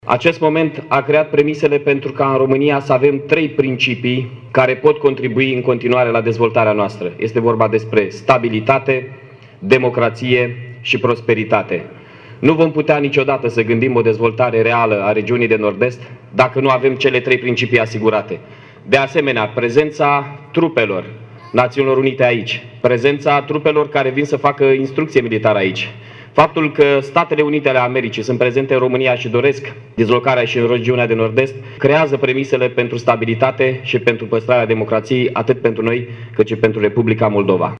La Iași, s-au desfășurat, cu acest prilej, manifestări în Piața Tricolorului din fața Prefecturii, unde au fost arborate drapelul românesc și steagul NATO.
Primarul interimar al Iașului Mihai Chirica a declarat că o dată cu aderarea României la NATO țara noastră nu a intrat doar într-un spațiu de securitate, ci și într-un spațiu de afirmare a valorilor democratice: